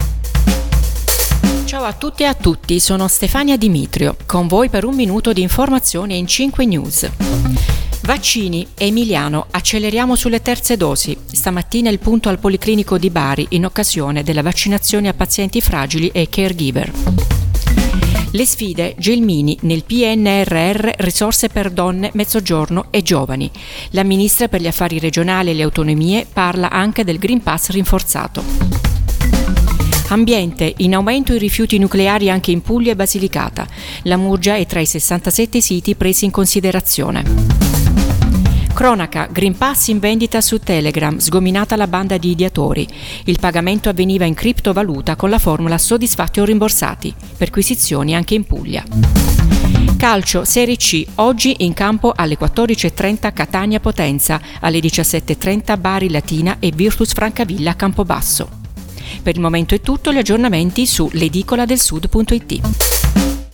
Giornale radio